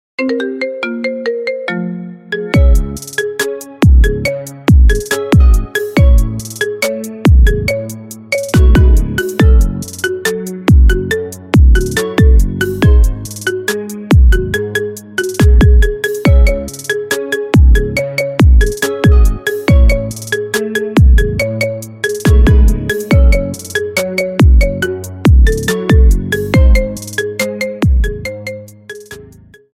Sonnerie Gratuite